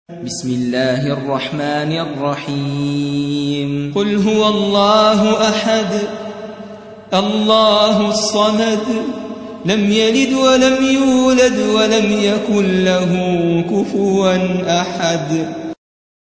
112. سورة الإخلاص / القارئ